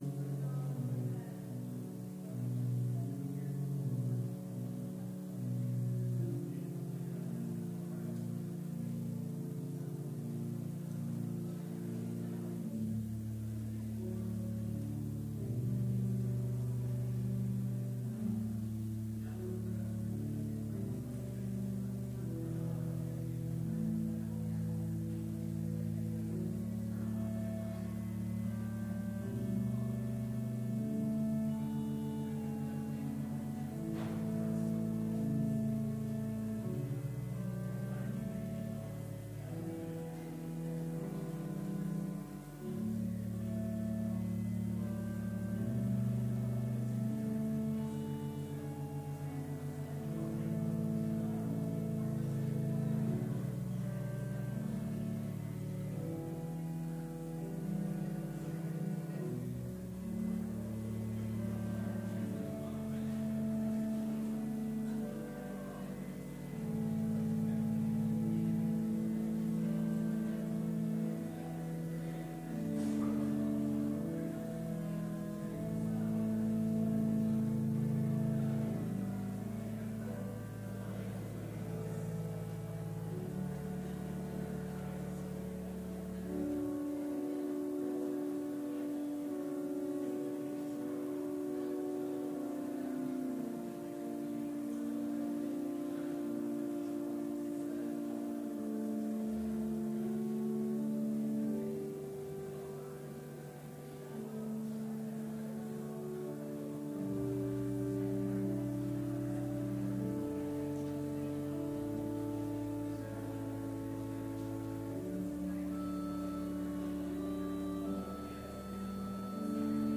Complete service audio for Chapel - February 6, 2019